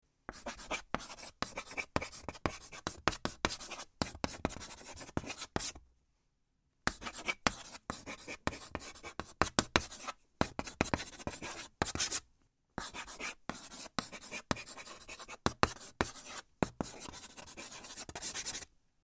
chalk.mp3